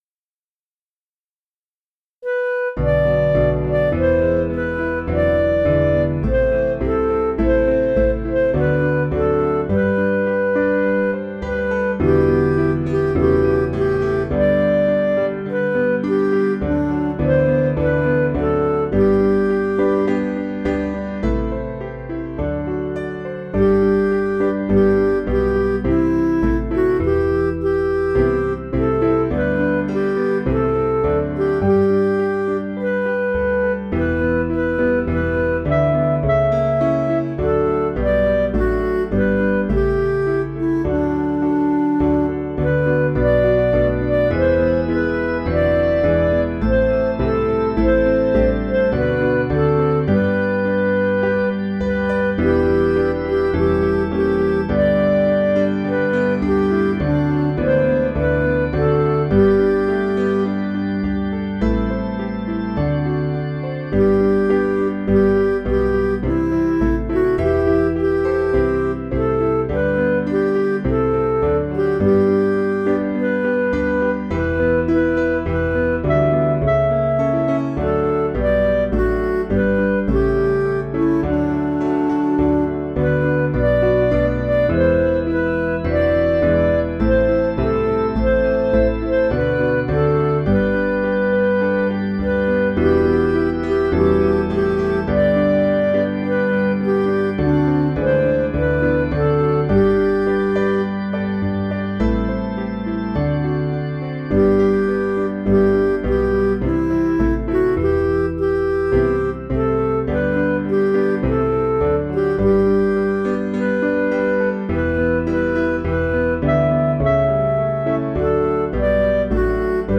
Piano & Instrumental
Midi